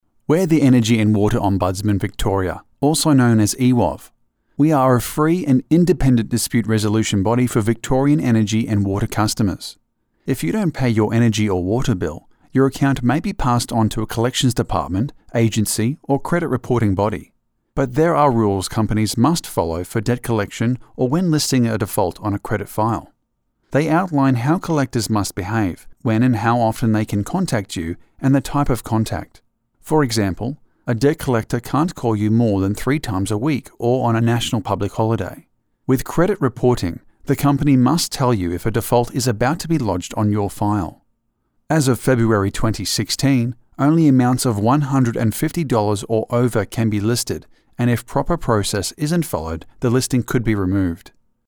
Professional, Natural, Conversational
englisch (australisch)
Sprechprobe: Industrie (Muttersprache):